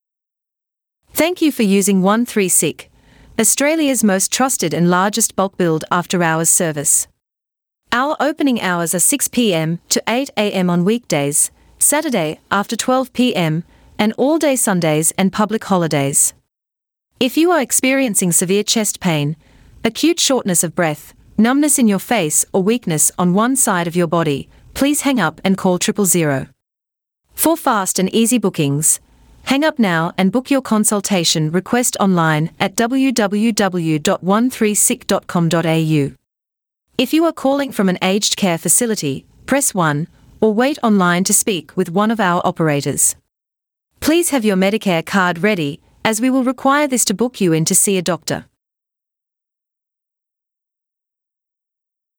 Welcome With IVR
Reading Speed💨: 1.0